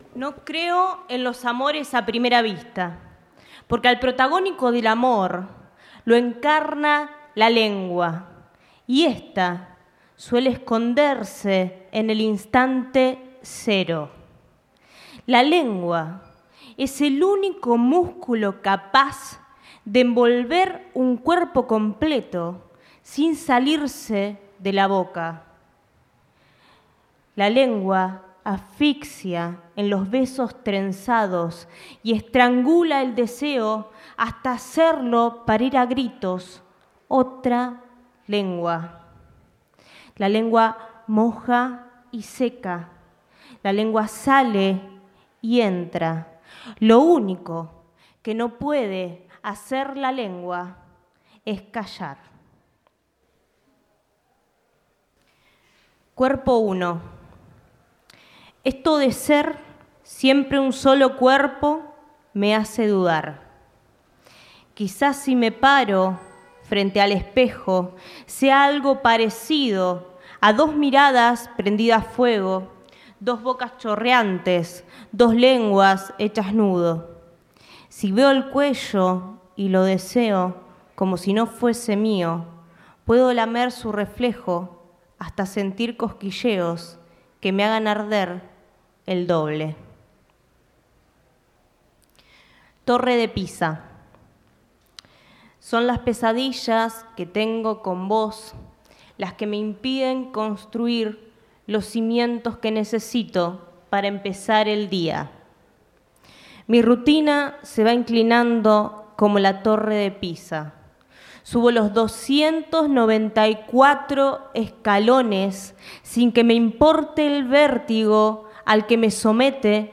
Charlas, conferencias, presentaciones
Presentación Revista Liso Santa Fe
Complejo Cultural Atlas Mitre 645, Ciudad de Rosario 19.30 hs. 15 de Marzo 2019